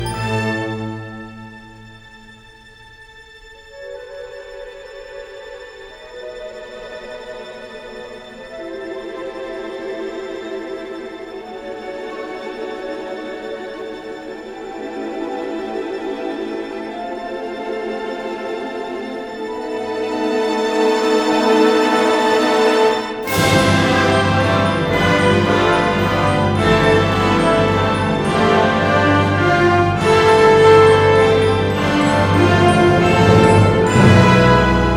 Жанр: Соундтрэки / Инструмантальные